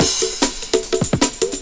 PERCLOOP1-R.wav